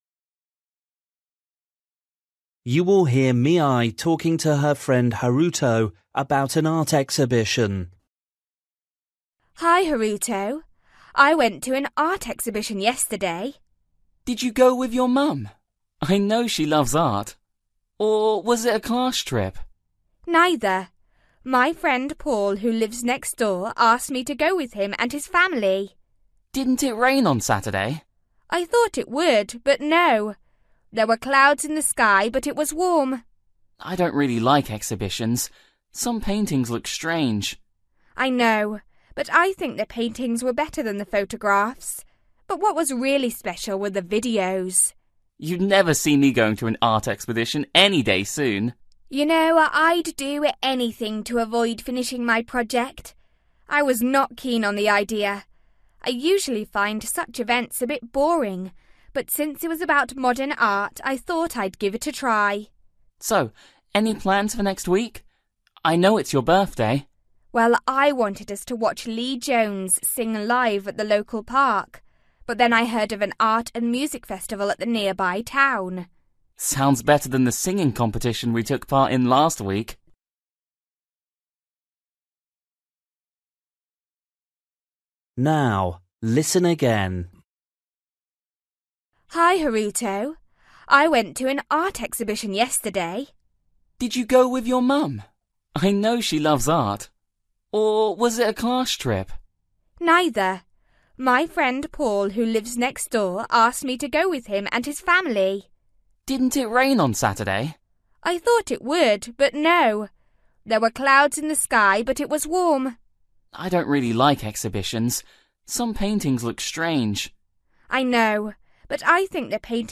Bài tập trắc nghiệm luyện nghe tiếng Anh trình độ sơ trung cấp – Nghe một cuộc trò chuyện dài phần 25